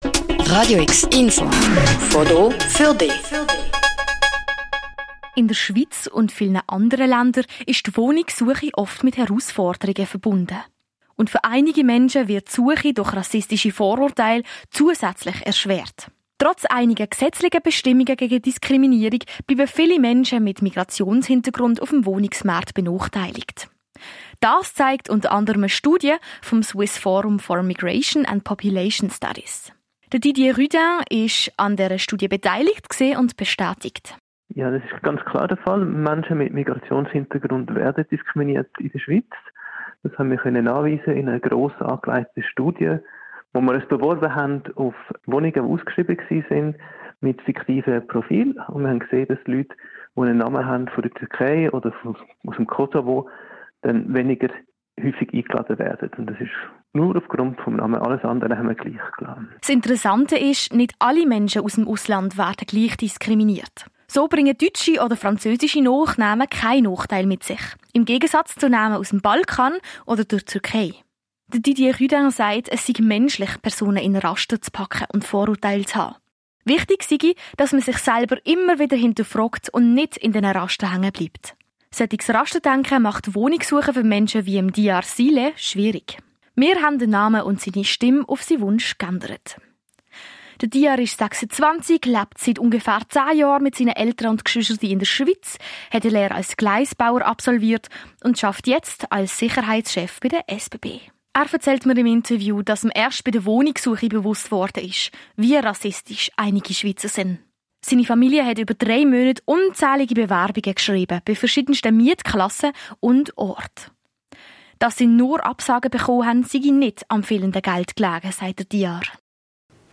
Wir sprechen mit einem Forscher, einem Betroffenen
und jemandem vom Mieterverband.